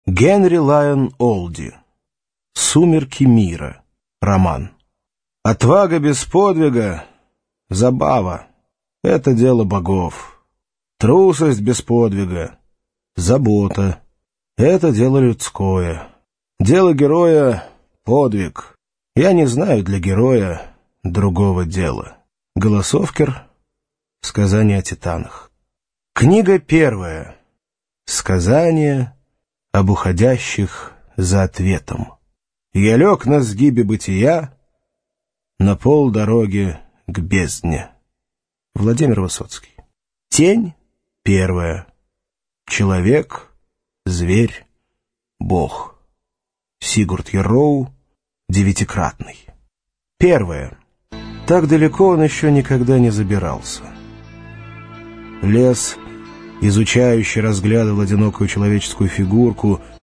Аудиокнига Сумерки мира | Библиотека аудиокниг